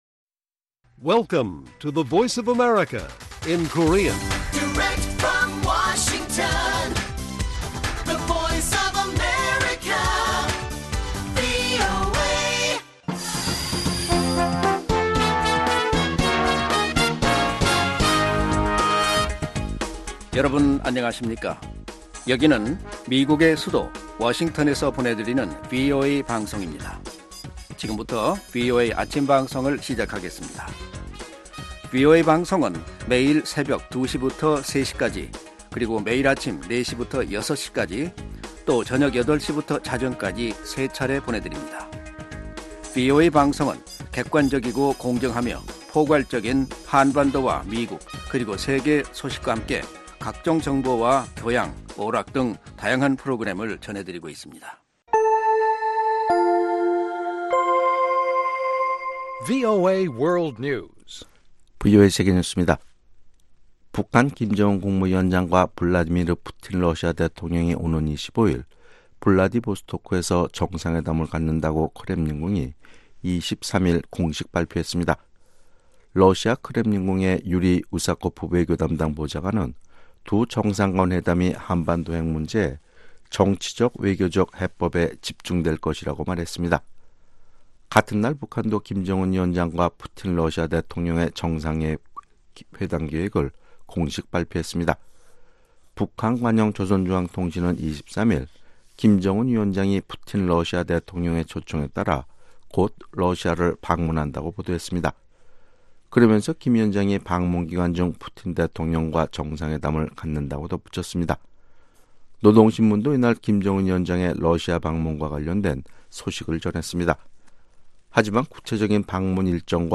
세계 뉴스와 함께 미국의 모든 것을 소개하는 '생방송 여기는 워싱턴입니다', 2019년 4월 24일 아침 방송입니다. ‘지구촌 오늘’은 중국이 해군 창설 70주년을 맞아 대규모 국제관함식을 가졌다는 소식, ‘아메리카 나우’ 에서는 트럼프 대통령과 트럼프그룹이 하원 정부개혁감독위원회를 상대로 소송을 냈다는 이야기를 소개합니다.